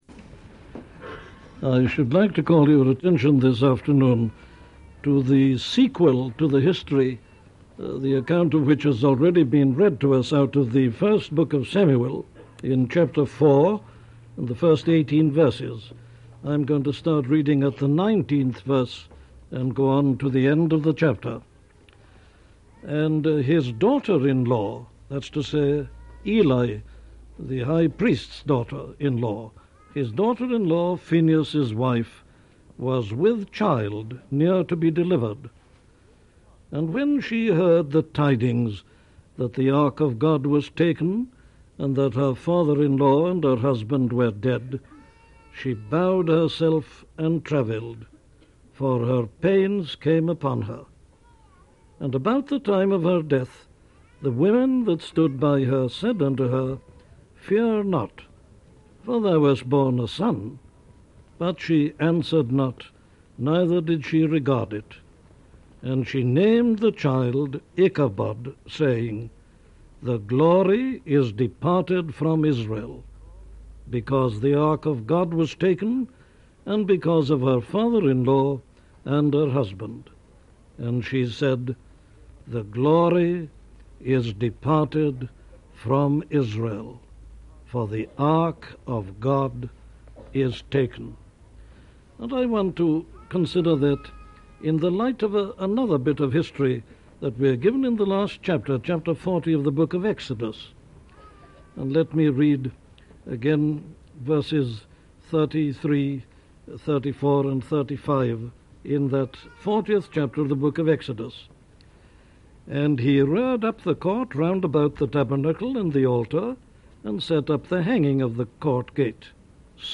The Presence of God - a sermon from Dr. Martyn Lloyd Jones
Listen to the sermon on 1 Samuel 4:21-22 'The Presence of God' by Dr. Martyn Lloyd-Jones